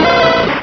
sovereignx/sound/direct_sound_samples/cries/growlithe.aif at master